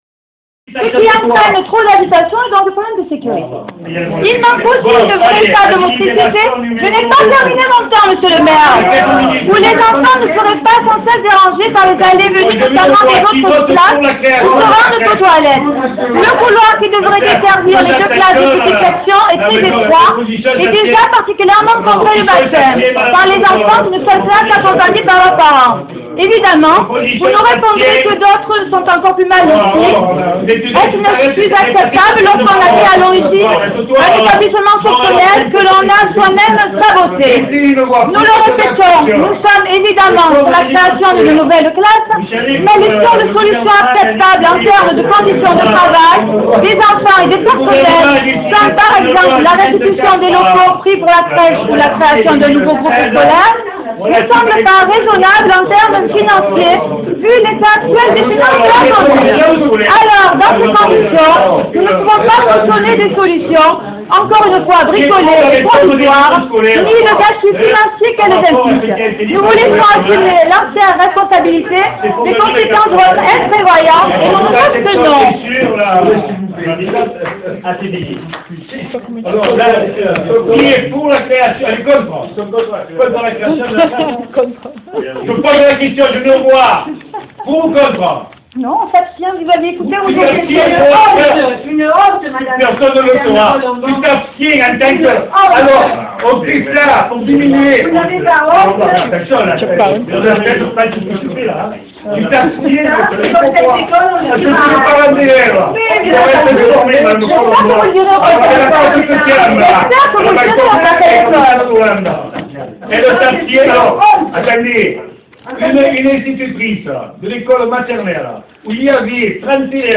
La réalité d'une opposition au sein du conseil municipal : extrait audio
Voici un extrait audio éloquent du conseil municipal du 4 mars 2013 où vous vous rendrez compte par vous-même de ce que représentent les notions de respect, écoute et démocratie pour monsieur le Maire et ses adjoints :
Conseil_municipal.mp3